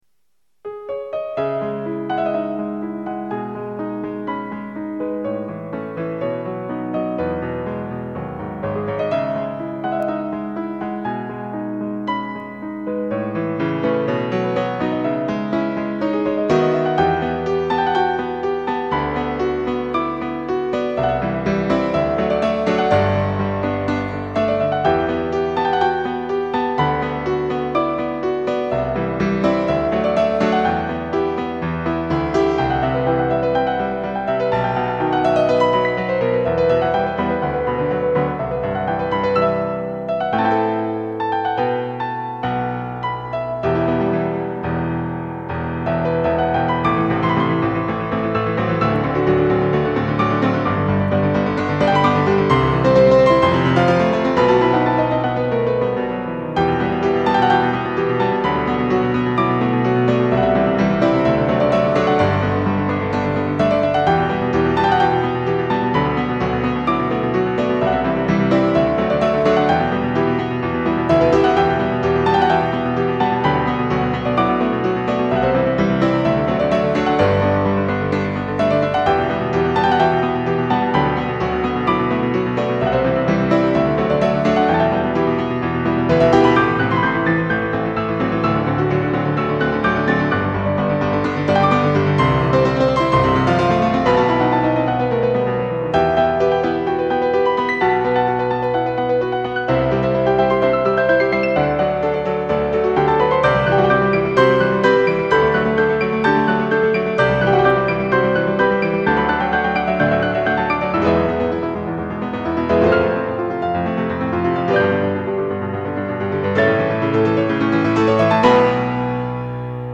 融合了民族乐器，如唢呐，笛子，和钢琴配合，在参入电子元素，使得乐曲在抒情上很具有渲染力